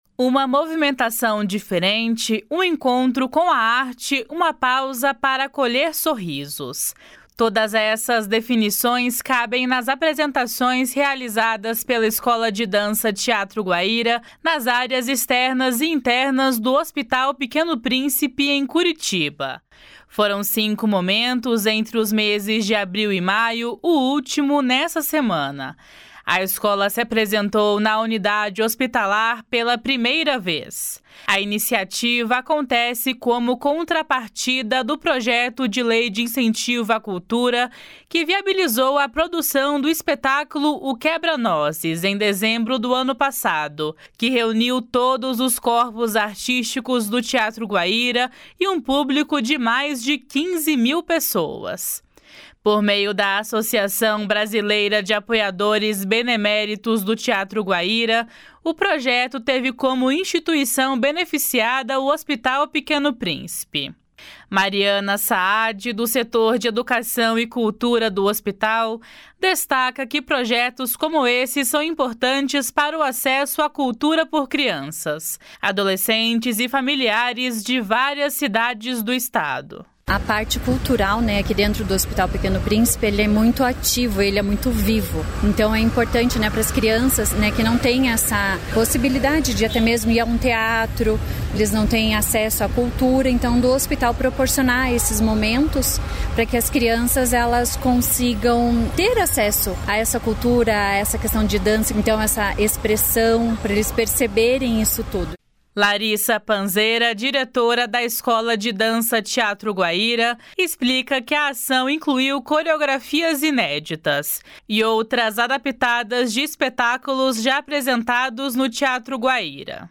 Essa é uma das extensões do programa Guaíra para Todos, que movimenta arte e cultura levando os corpos artísticos do Teatro Guaíra para outros espaços. (Repórter